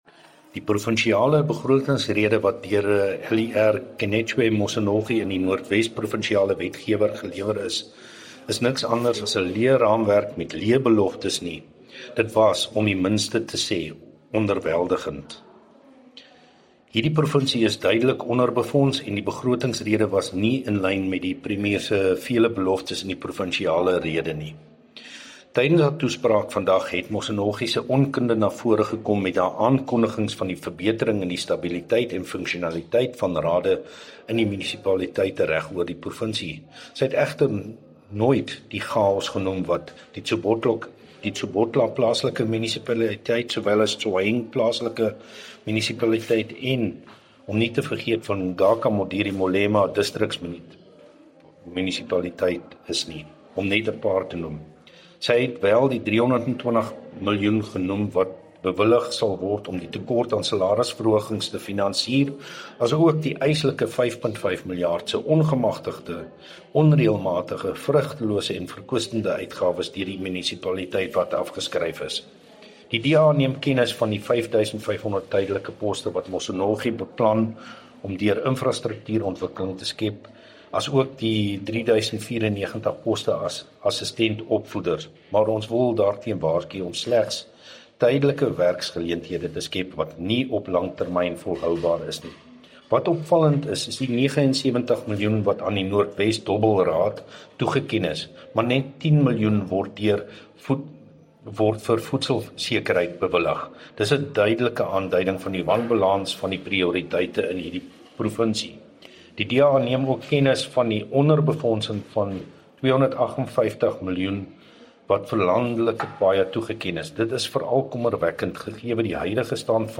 Issued by Wolfgang Wallhorn – DA Spokesperson on Finance in the North West Provincial Legislature
Afrikaans by Wolfgang Wallhorn